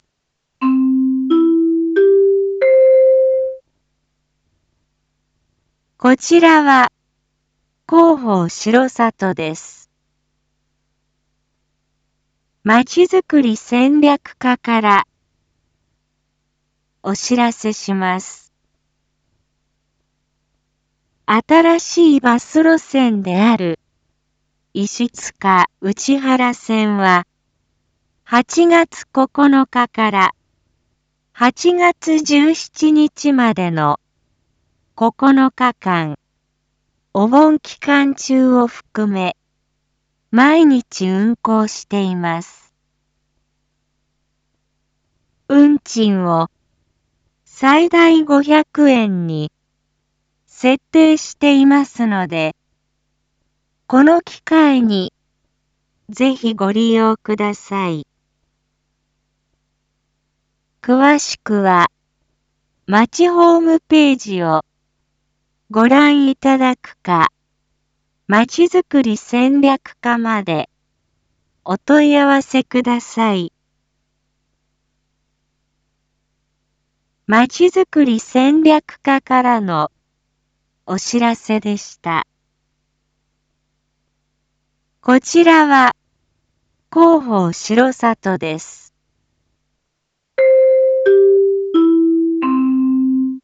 一般放送情報
Back Home 一般放送情報 音声放送 再生 一般放送情報 登録日時：2025-08-11 19:01:35 タイトル：石塚・内原線のお盆期間の運行② インフォメーション：こちらは広報しろさとです。